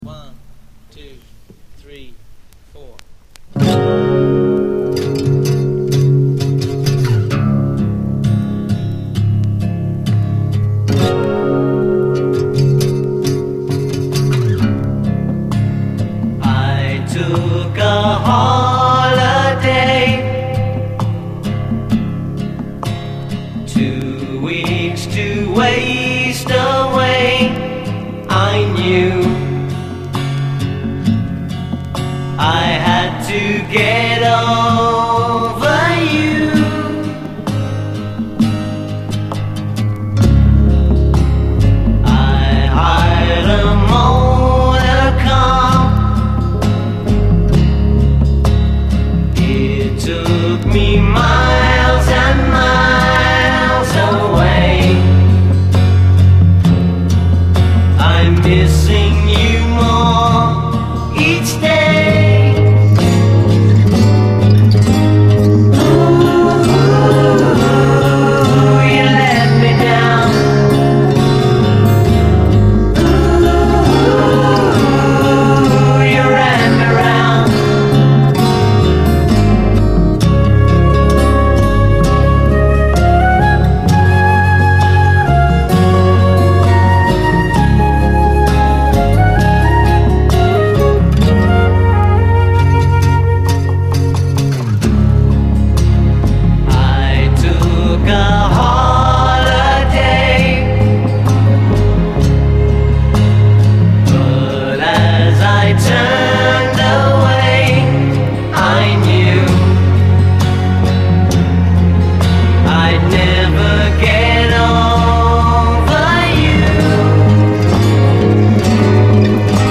強力ブラス＆ギター＆ストリングスがギンギンにうねるサイケデリック・ファンク
ストリングス＆コーラスの清らかさが胸をうつビューティフル・ソフト・ロック〜ブルーアイド・ソウル
猥雑なワウ・ギターと清らかなコーラスが同居するファンキー・ソウル